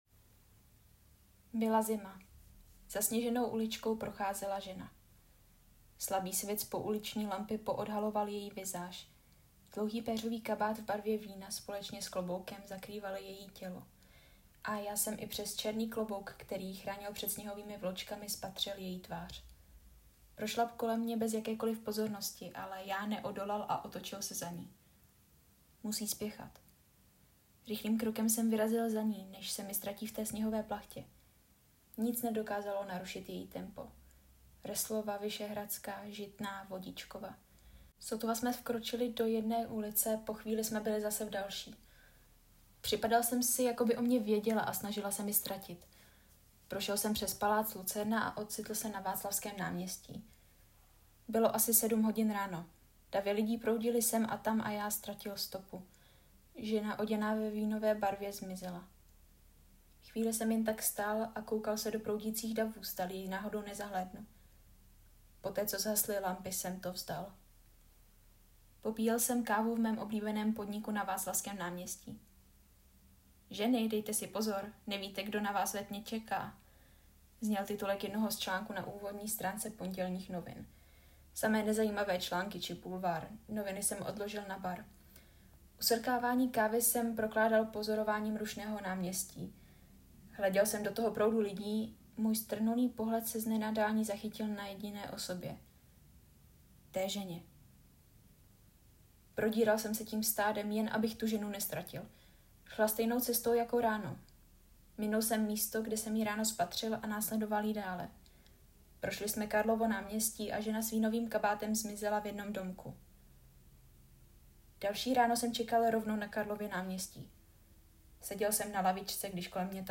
Vyprávění na pomezí krimi a mysteriózního žánru.